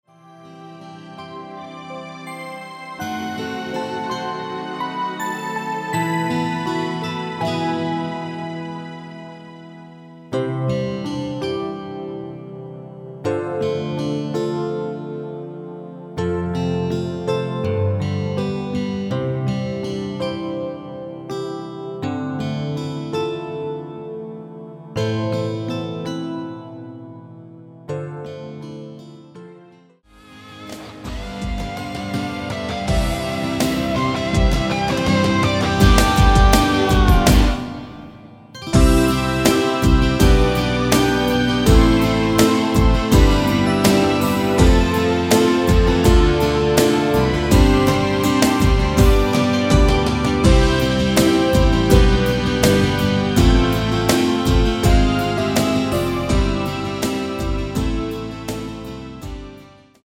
대부분의 남성분이 부르실수 있는 키로 제작 하였습니다.
원키에서(-9)내린 MR입니다.
앞부분30초, 뒷부분30초씩 편집해서 올려 드리고 있습니다.
중간에 음이 끈어지고 다시 나오는 이유는